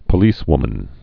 (pə-lēswmən)